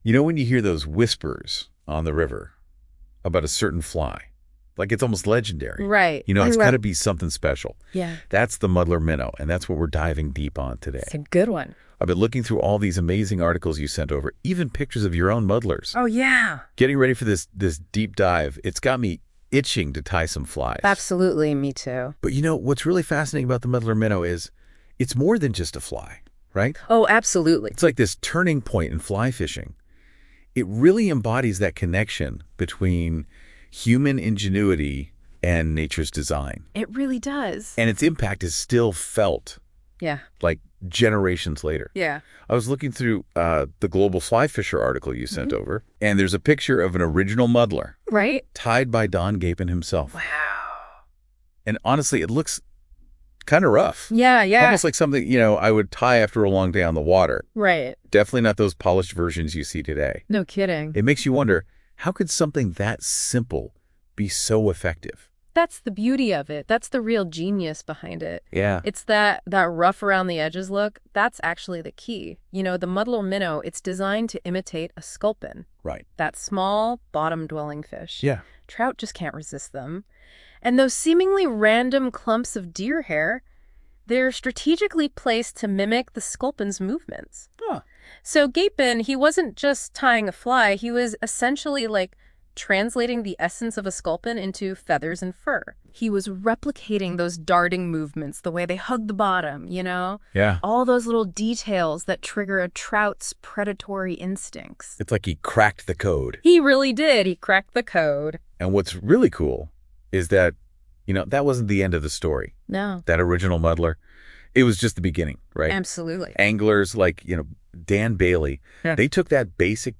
In other words an artificially generated sound file, where two “virtual” people discuss the text.
It chewed on the text for a few minutes, and then served me a small sound-file with 8½ minutes of conversation between a male and a female where they talk about muddlers with the outset in my article.
They engage in the most natural conversation – actually almost over-natural with hm’s, uh’s and wow’s all the right places and a very chatty and almost overly happy tone – which seems to reflect a deep fascination of muddlers and fly tying.
It is sometimes a little high flying, but in general, just comes over as very enthusiastic.